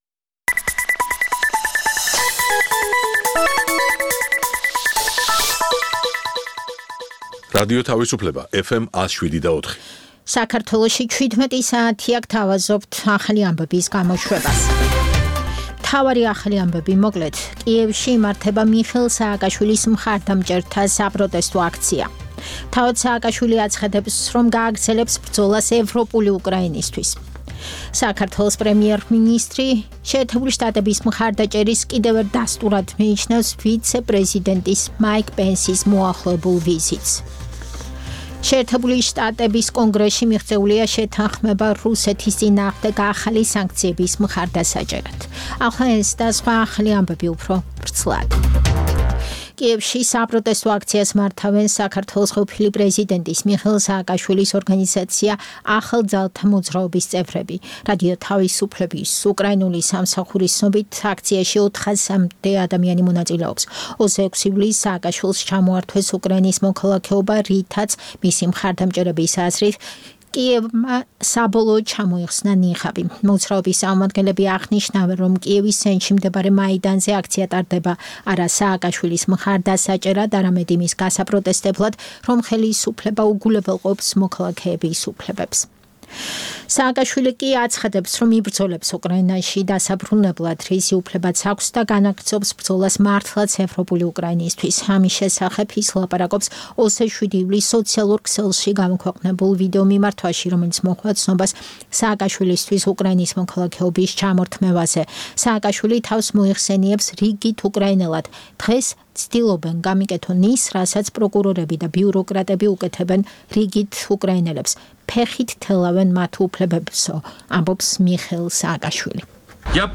ცნობილ ექსპერტებთან ერთად, გადაცემაში მონაწილეობენ საზოგადოებისთვის ნაკლებად ცნობილი სახეები, ახალგაზრდა სამოქალაქო აქტივისტები. გამოყენებულია "რადიო თავისუფლების" საარქივო მასალები, რომელთაც გადაცემის სტუმრები "დღევანდელი გადასახედიდან" აფასებენ.